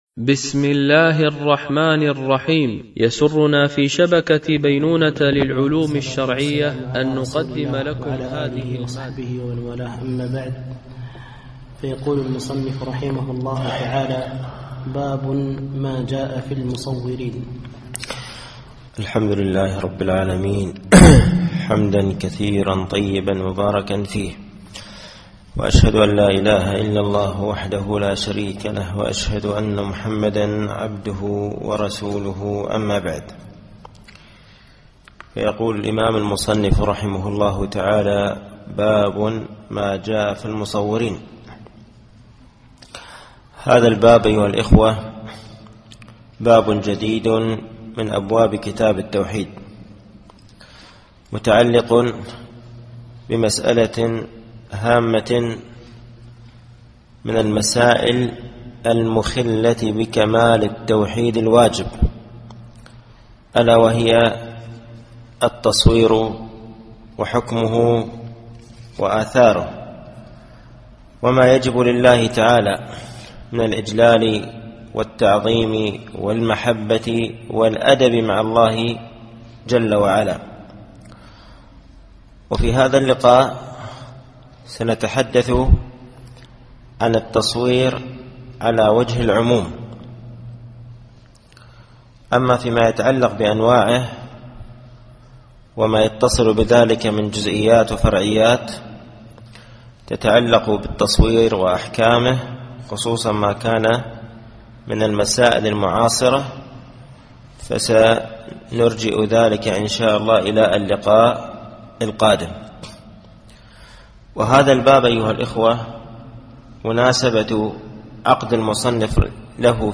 التعليق على القول المفيد على كتاب التوحيد ـ الدرس الحادي و الستون بعد المئة